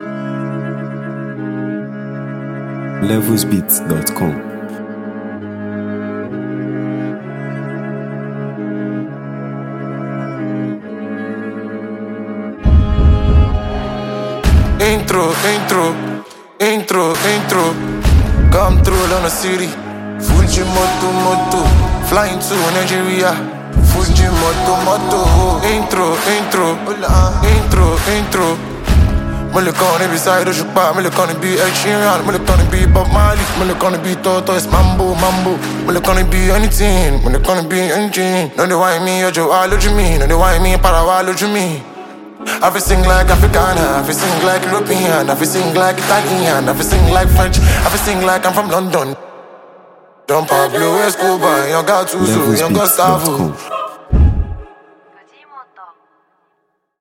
Nigeria Music 2025 0:57
the multi-talented Nigerian singer and songwriter
This electrifying tune